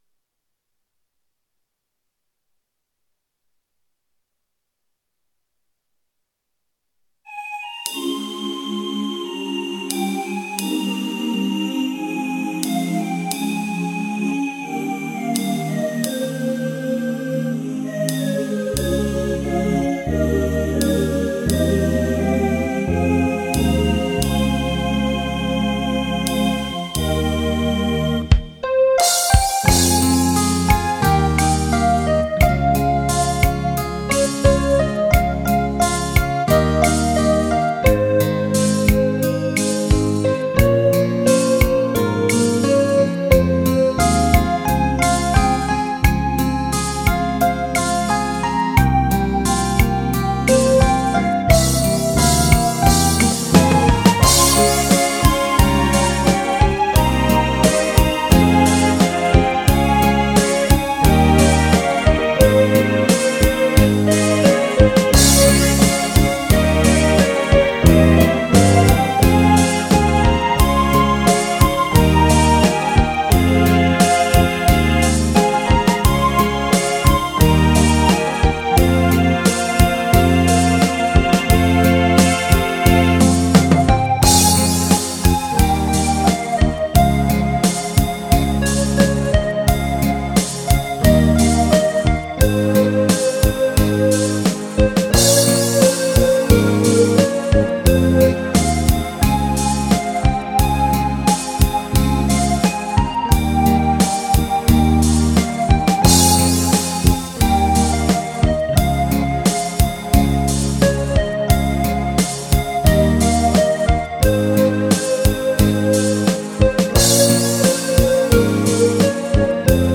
楽器はエレクトーン。生演奏録音。
コードの流れとメロディーラインに命かけてた。